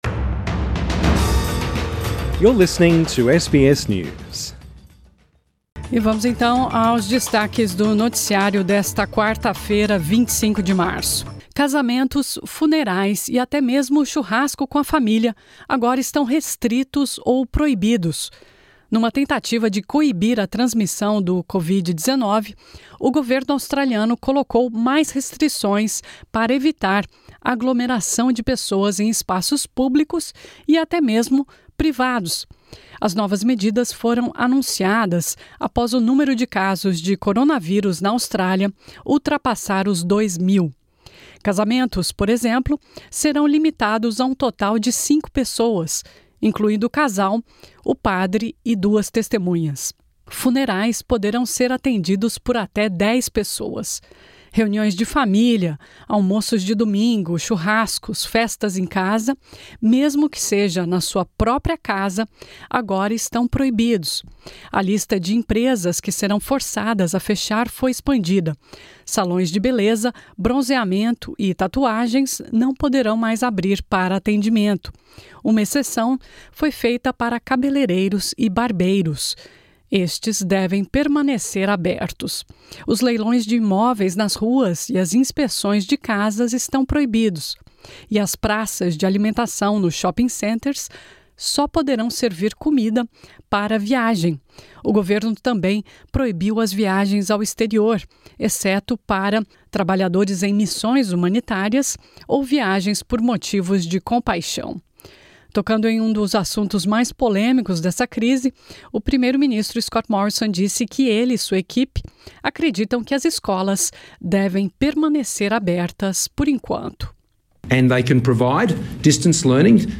Casamentos, funerais e até mesmo o churrasco de domingo com a família agora estão restritos ou proibidos na Austrália. Ouça esse e outros destaques do noticiário desta quarta-feira 25 de março.